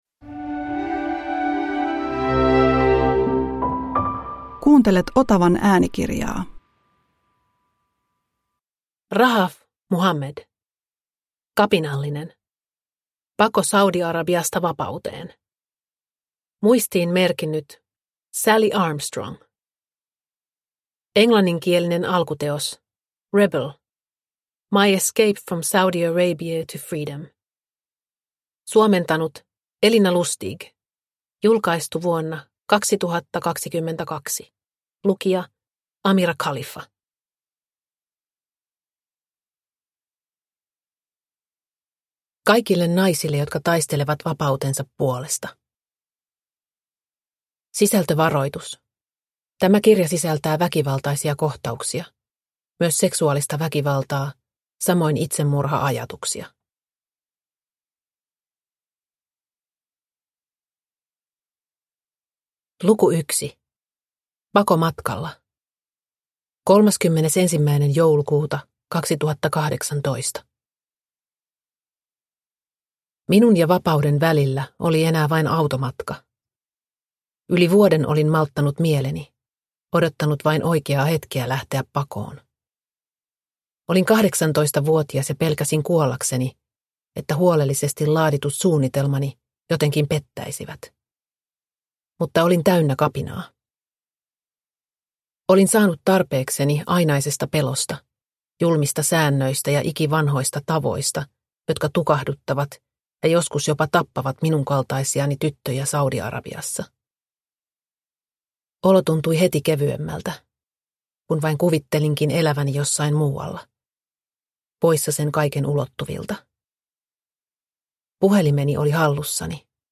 Kapinallinen – Ljudbok – Laddas ner